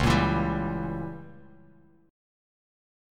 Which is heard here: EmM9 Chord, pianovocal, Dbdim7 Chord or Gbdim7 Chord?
Dbdim7 Chord